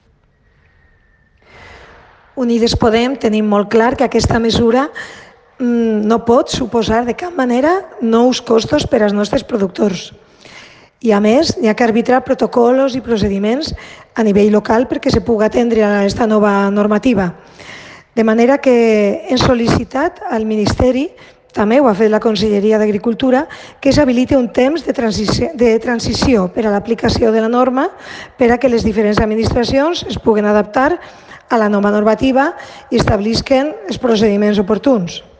Corte de voz de la diputada nacional de Unidas Podemos, Marisa Saavedra